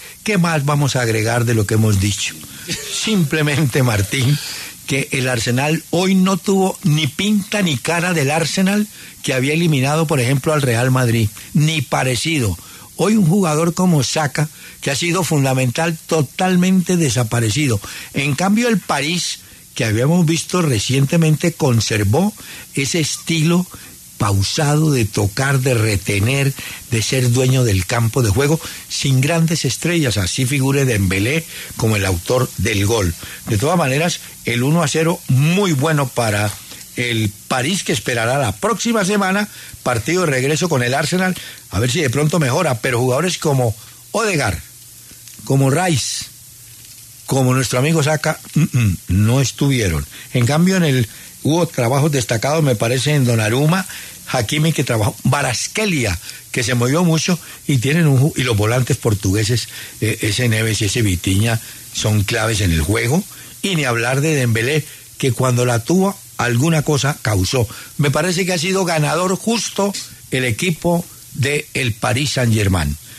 Análisis de Hernán Peláez del partido Arsenal vs PSG
El reconocido periodista deportivo, Hernán Peláez, analizó en los micrófonos de W Radio el compromiso entre Arsenal y PSG por el partido de ida de las semifinales de la Champions League y resaltó el juego del conjunto dirigido por Luis Enrique.